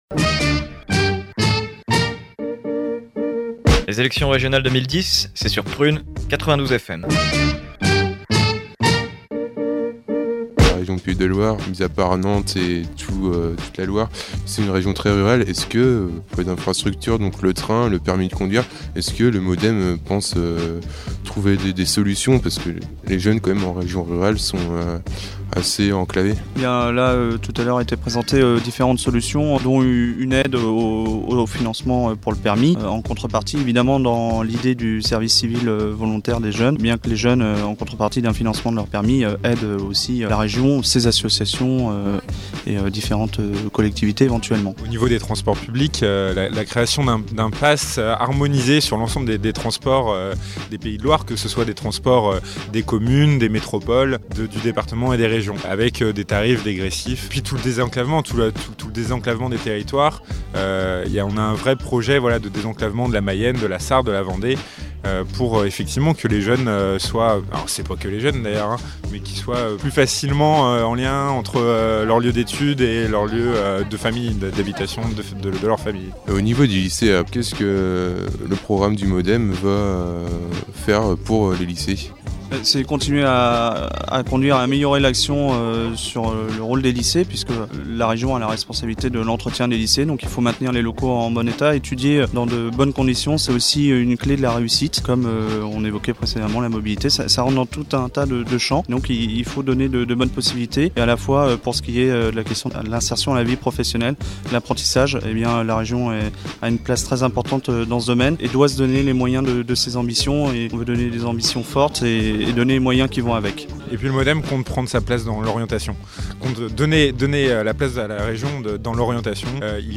Samedi 13 Février, rencontre avec des militants du Mouvement démocrate. Ils reviennent, au micro de Prun’, sur les propositions du Modem en matière de transport et d’éducation.